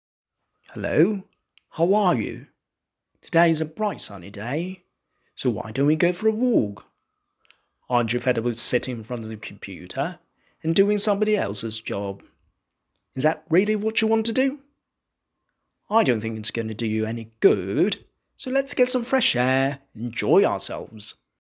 另外，這裡有一篇範文，總共十句，剛好運用了十大英文語調各一次。